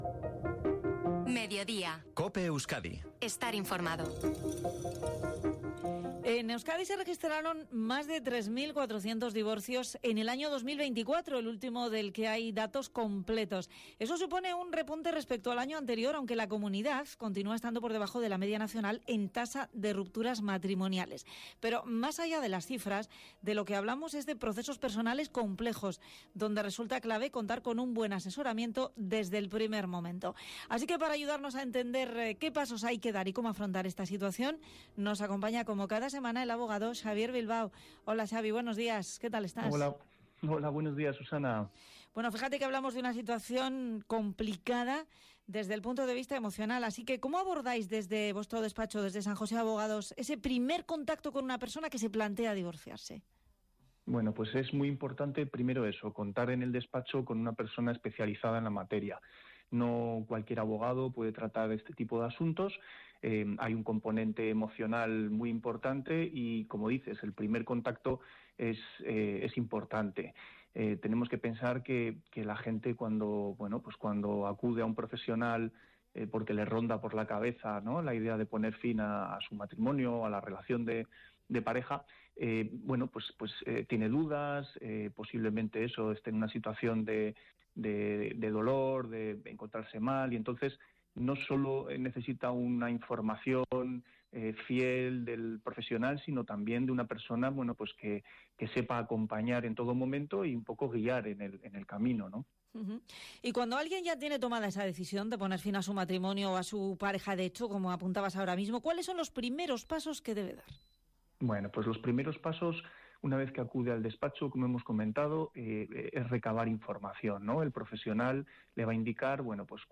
ENTREVISTA EN COPE SOBRE EL DIVORCIO - Despacho Abogados San Jose